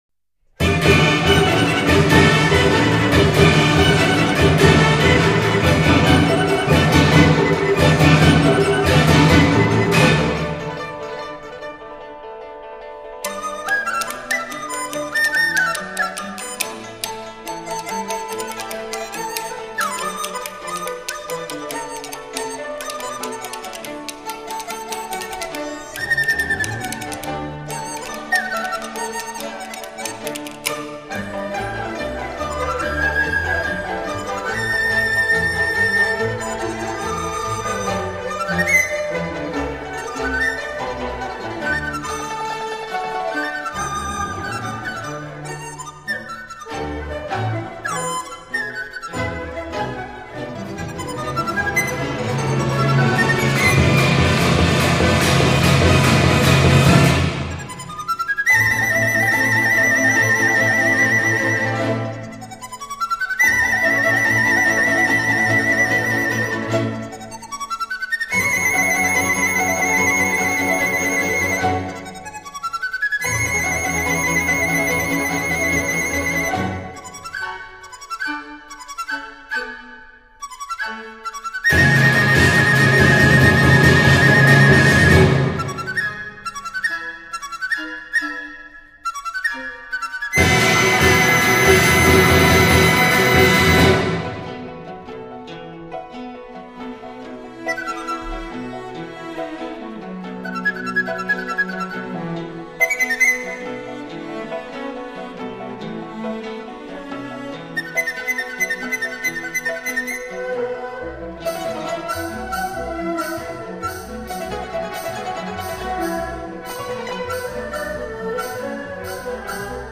唱片类型：民族音乐
专辑语种：纯音乐